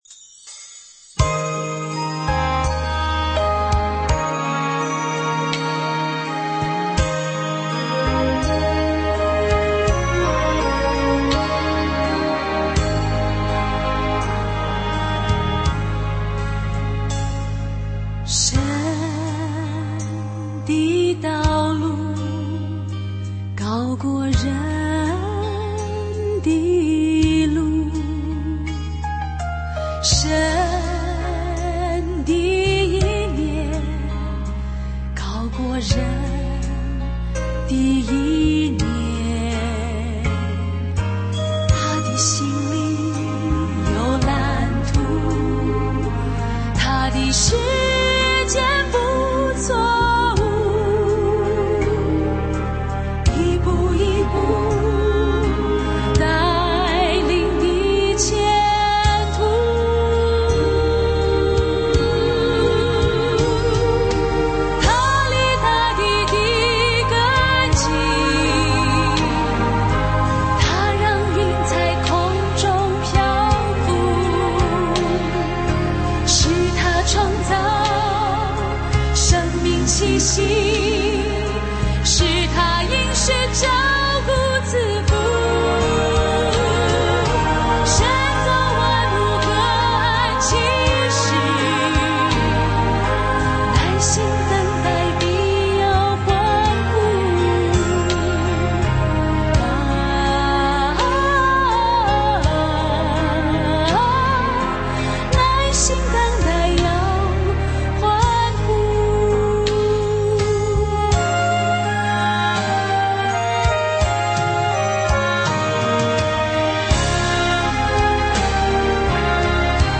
领唱][伴奏]